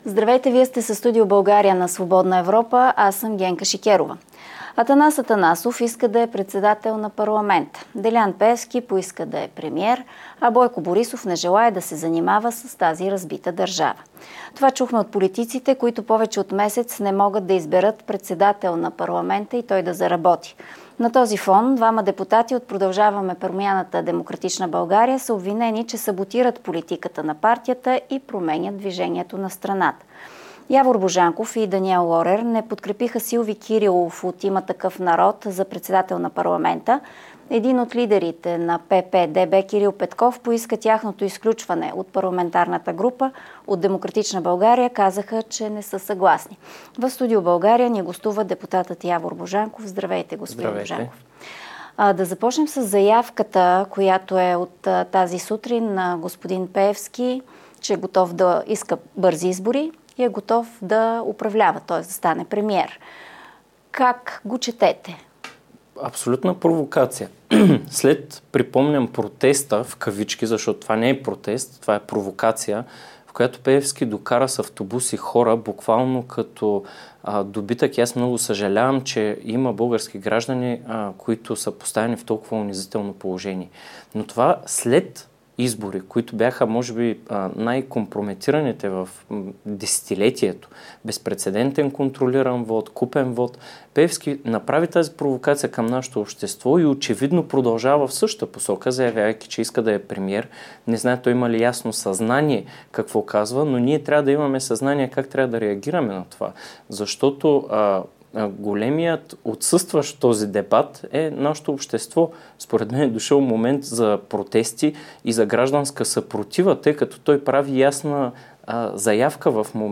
Може ли с "Възраждане" да се изолира Пеевски? Говори Явор Божанков